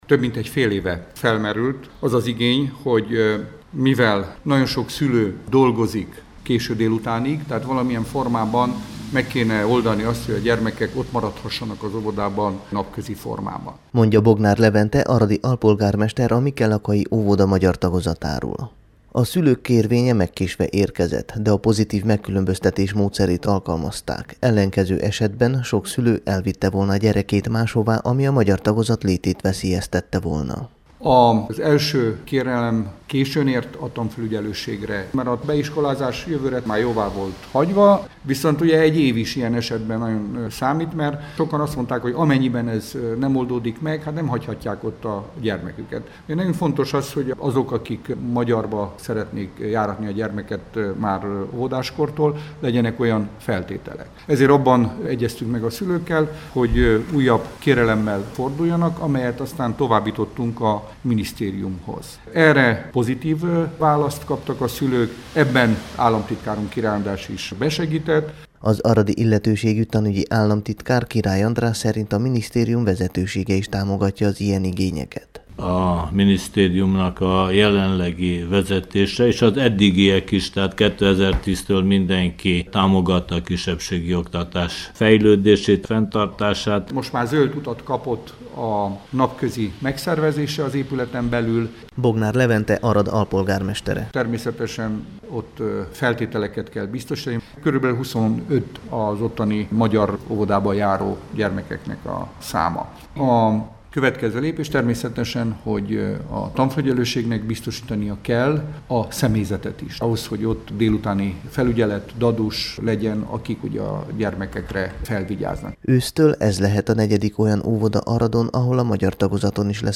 tanügyi helyzetjelentése Aradról a Temesvári Rádió számára készült